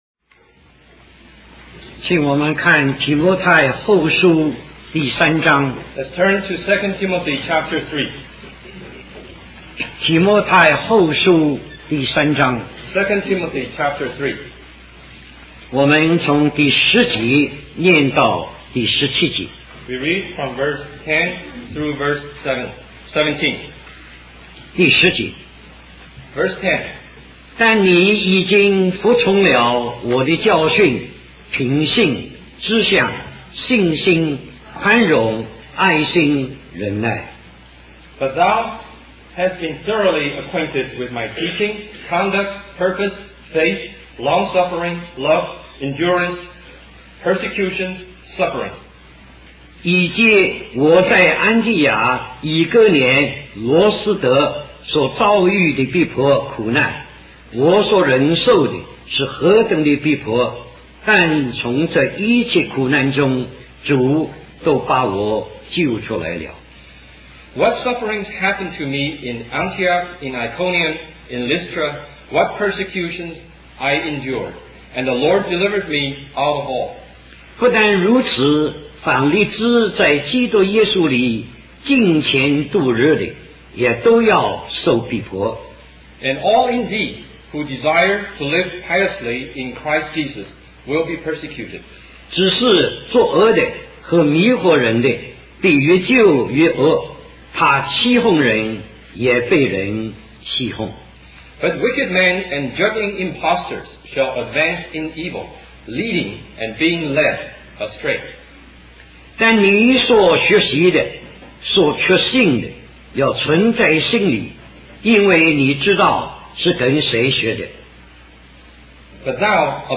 A collection of Christ focused messages published by the Christian Testimony Ministry in Richmond, VA.
Conference at Bible Institute of Los Angeles